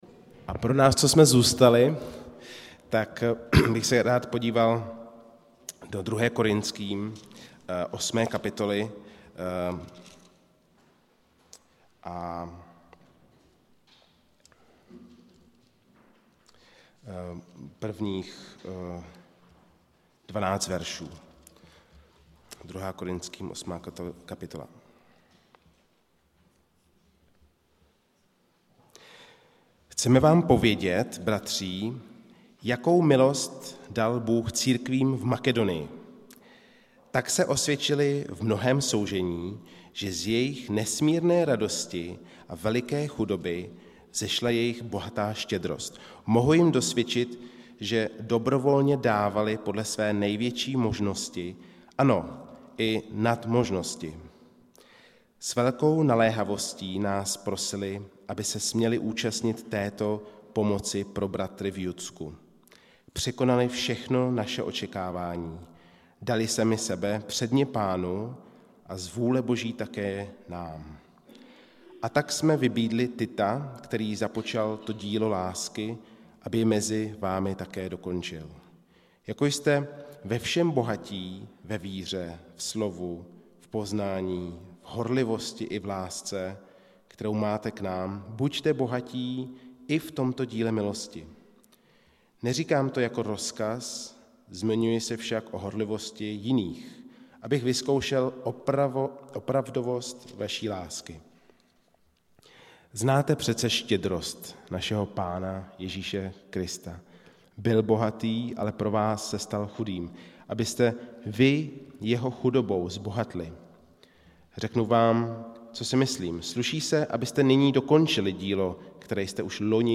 Událost: Kázání
Místo: Římská 43, Praha 2